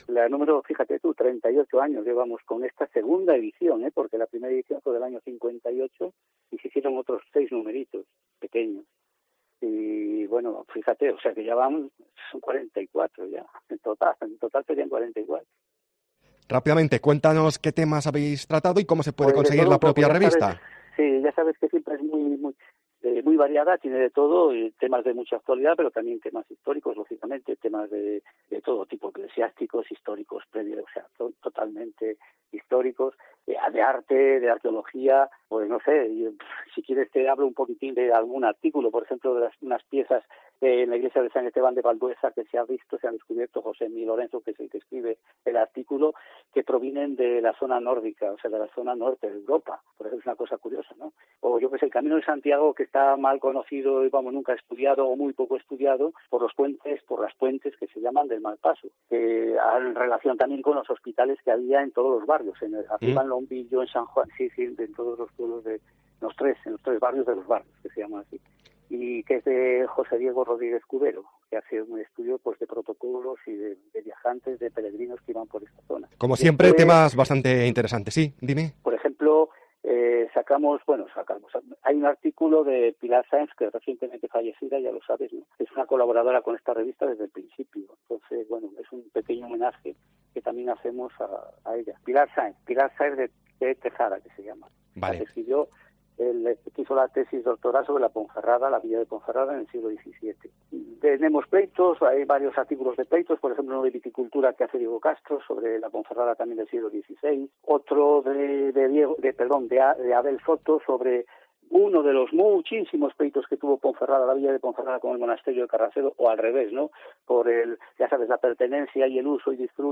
historiador